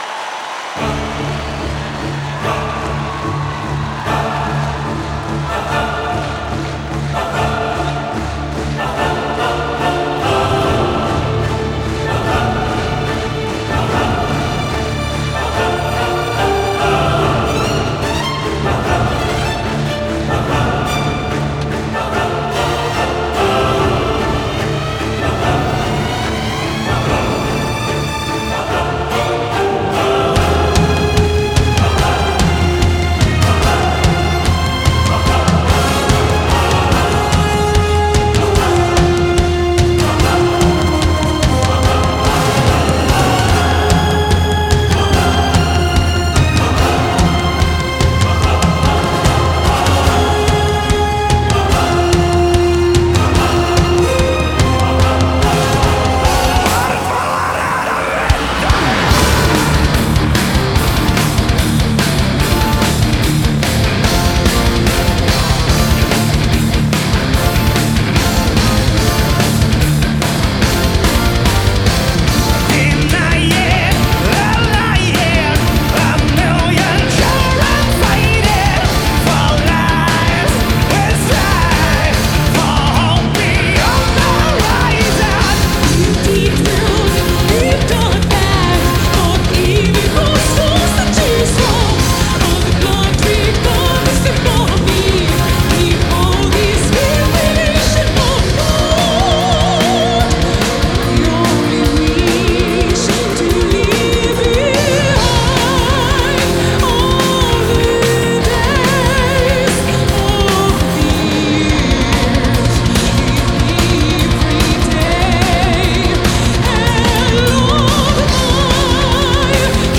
Genre: Symphonic Rock